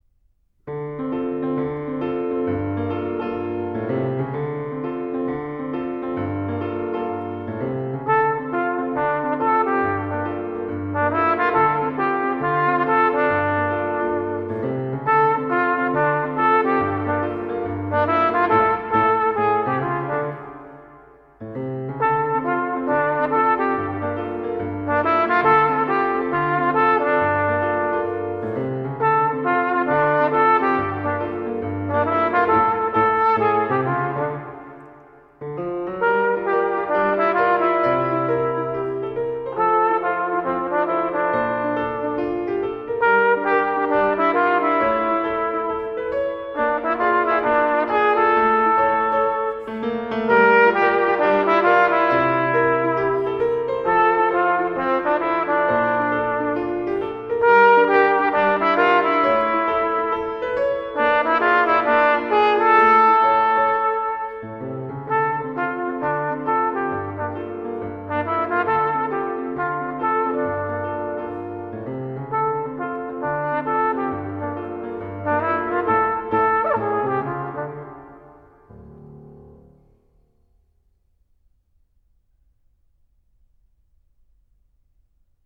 Gattung: Trompete und Klavier inkl.